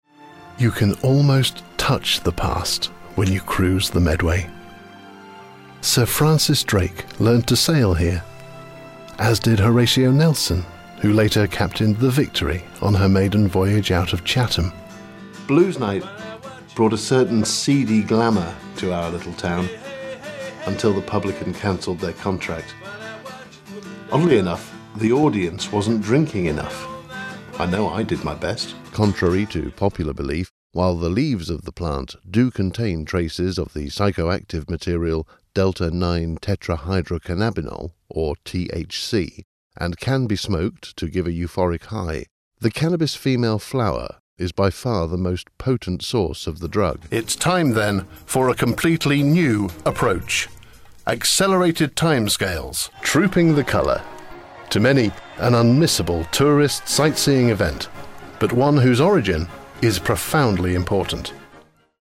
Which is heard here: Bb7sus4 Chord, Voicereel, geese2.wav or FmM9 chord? Voicereel